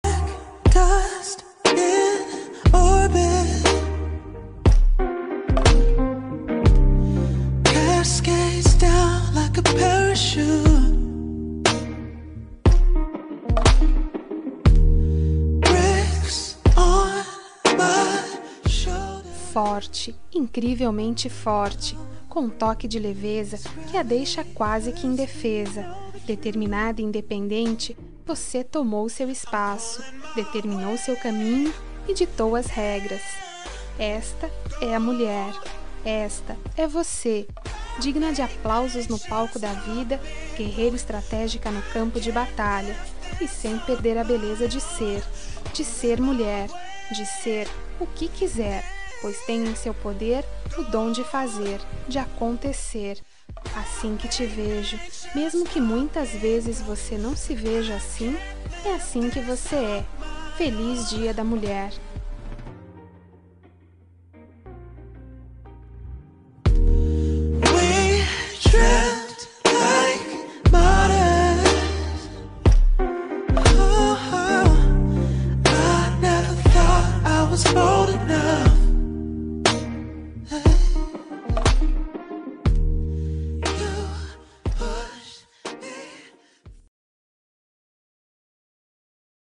Dia das Mulheres Neutra – Voz Feminina – Cód: 5270